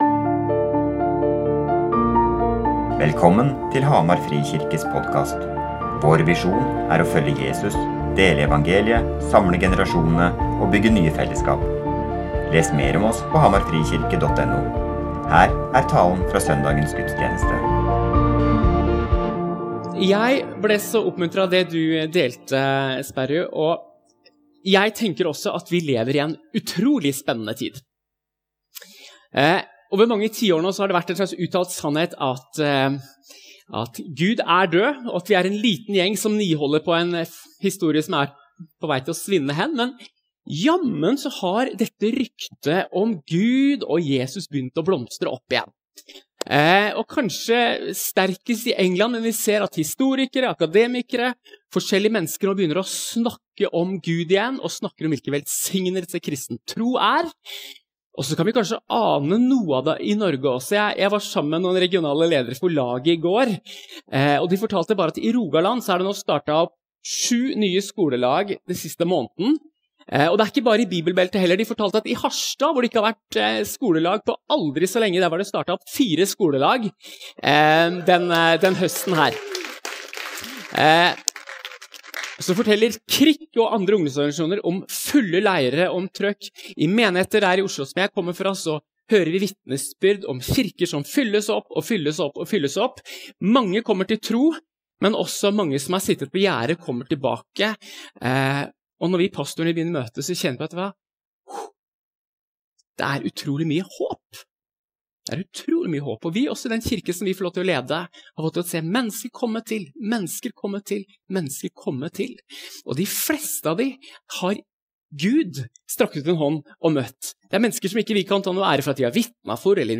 Tale basert i Johannes 17,20-23, til fellesmøte mellom menigheter i Hamar, arrangert av Sammen for Hamar.